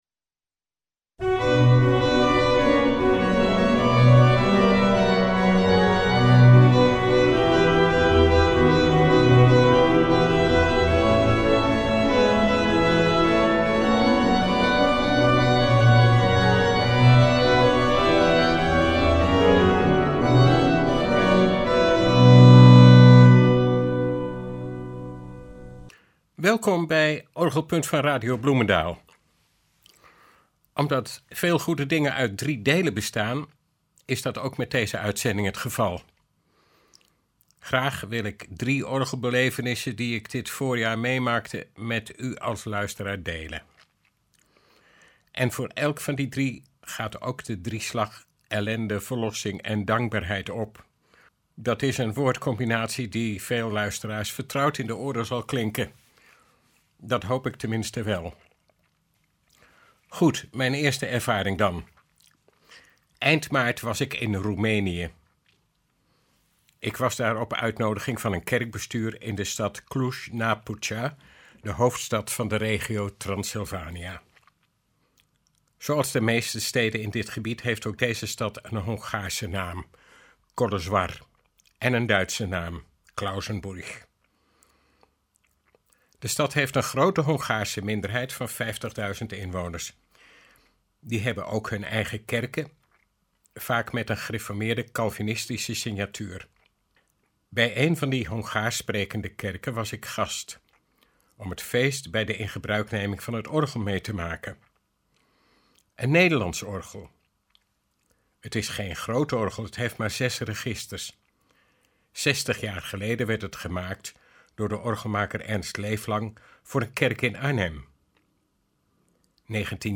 Vervolgens horen we het orgel van de Julianakerk in Veenendaal (foto boven) flink onderhanden werd genomen en gereorganiseerd.